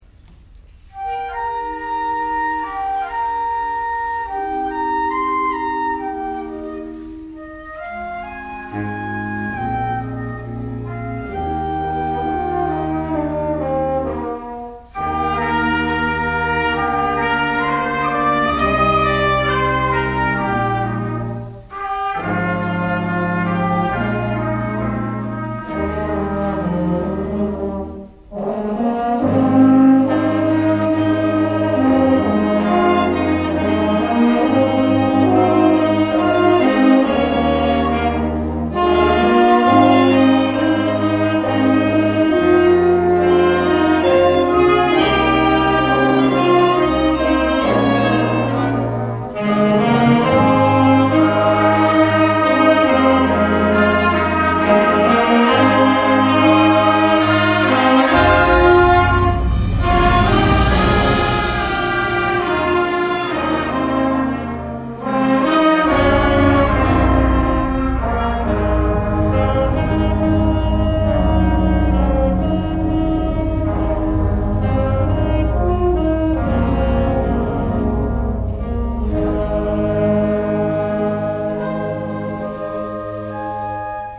第１４回南那須地区音楽祭にブラスバンド部が出場しました。
迫力ある演奏をお聴きください。